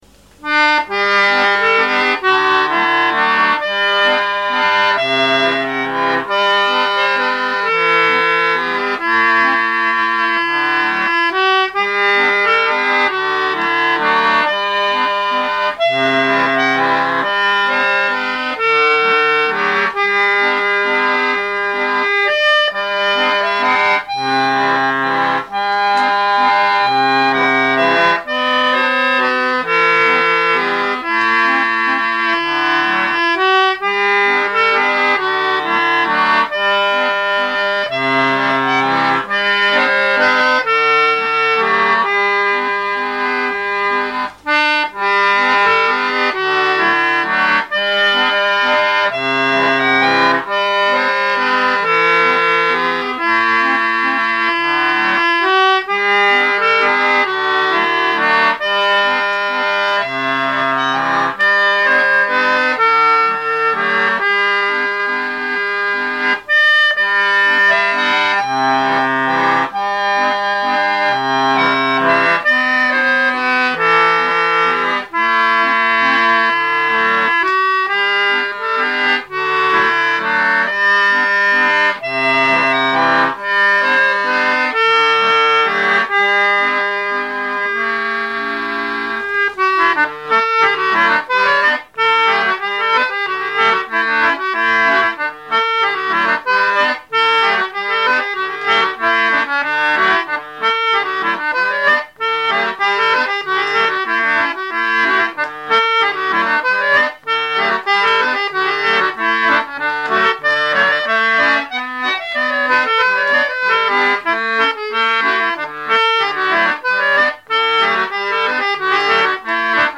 all "single take" onto a mini Sanyo cassette recorder
are played on a 48 button ebony ended New Model Lachenal